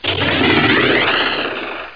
00091_Sound_Rocket.mp3